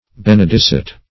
Benedicite \Ben`e*dic"i*te\, interj. [See Benedicite, n.]